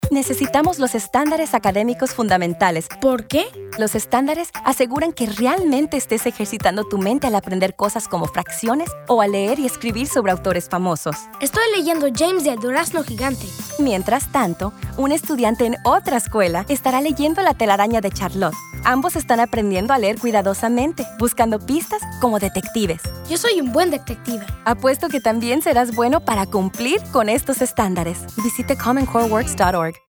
Radio PSAs
Spanish Conversation.mp3